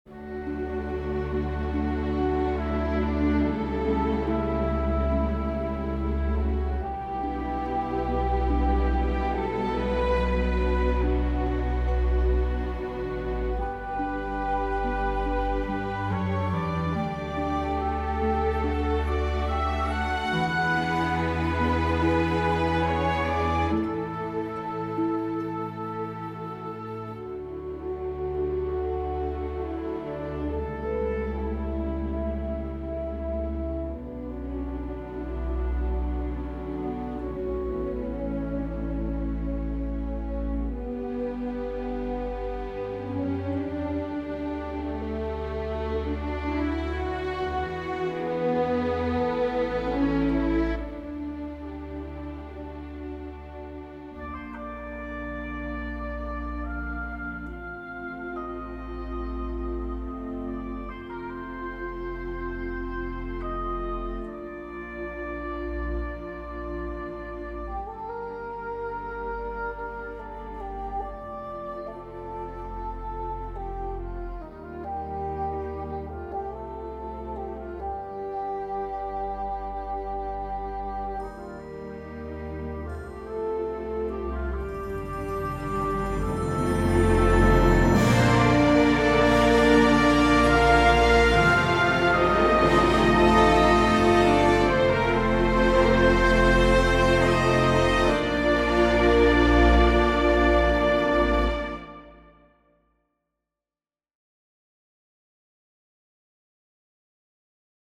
The piece showcases an interplay of solo instruments, weaving together to create a unique and intriguing tonal color.